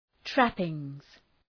{‘træpıŋz}